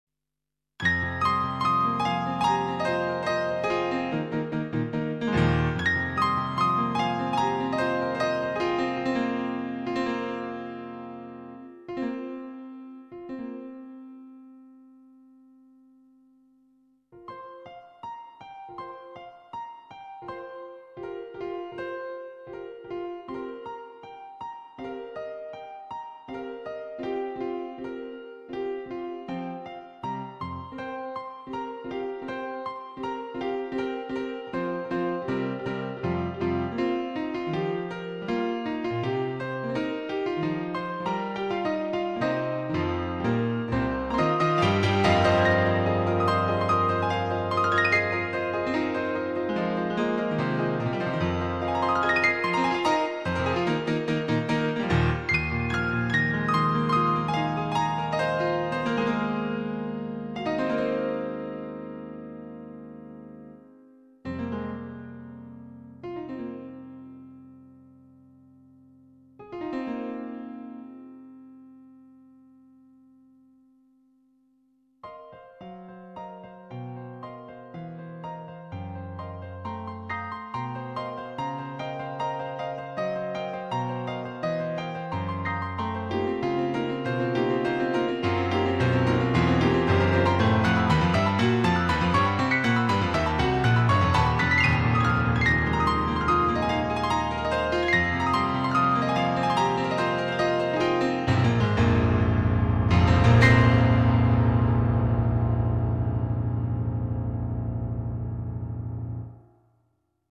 nhạc độc tấu
cho đàn piano độc tấu
dân ca Mèo